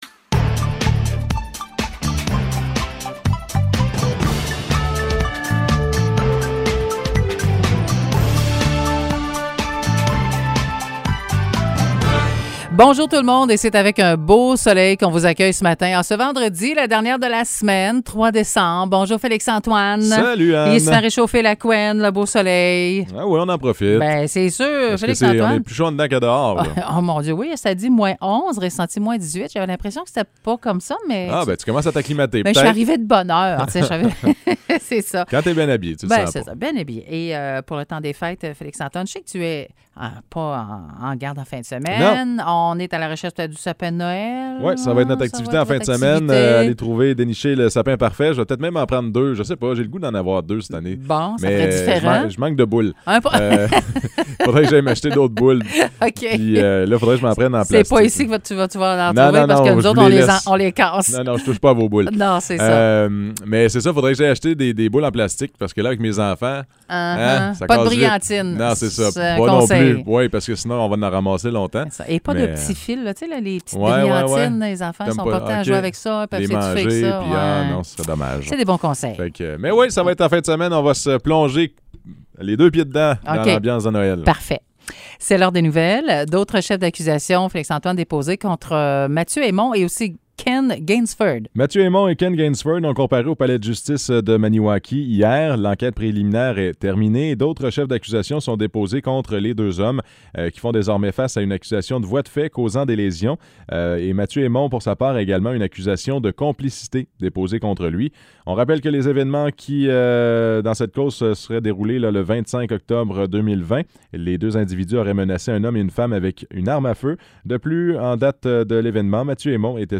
Nouvelles locales - 3 décembre 2021 - 9 h